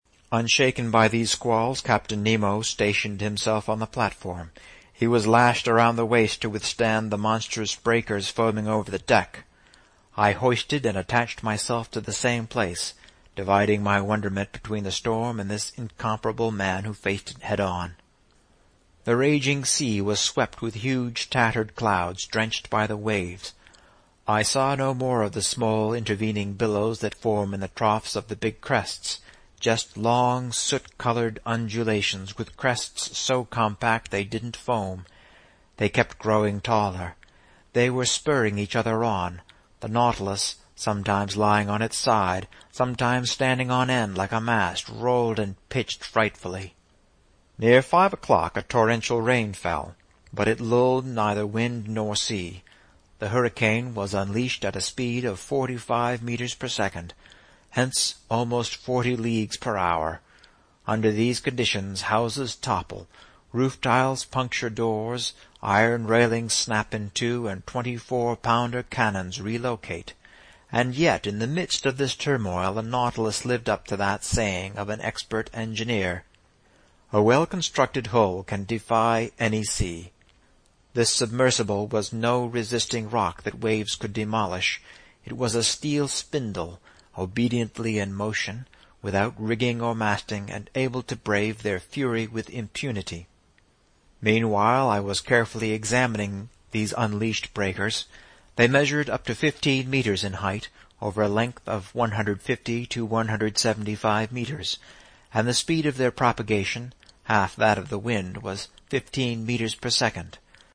英语听书《海底两万里》第523期 第32章 海湾暖流(14) 听力文件下载—在线英语听力室